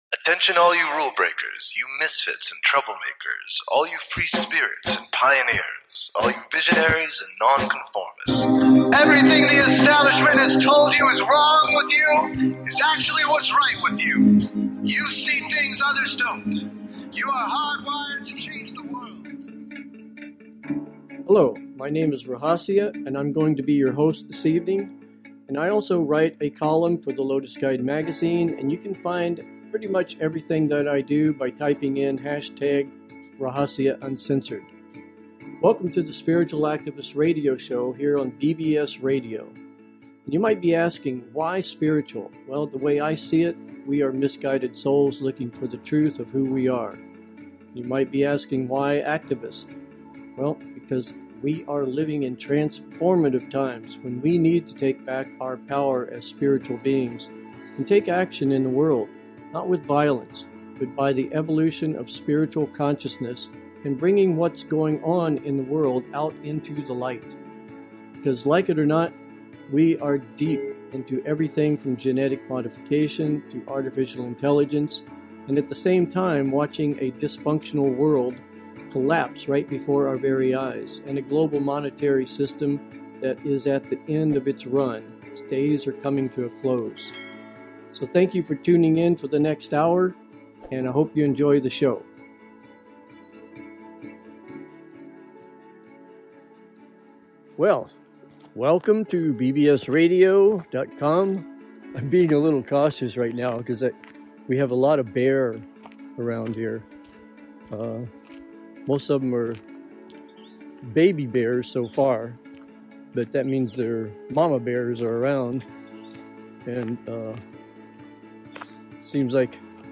Talk Show Episode
Walk & Talk About My Life and Our World